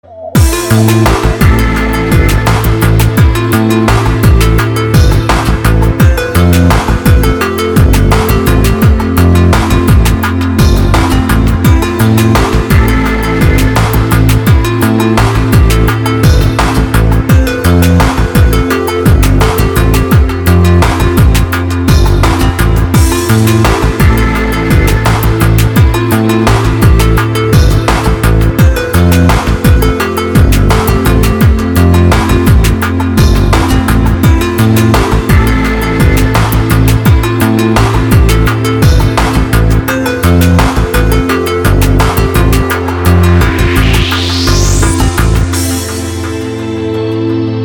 • Качество: 224, Stereo
красивые
без слов
космические
Космическая музыка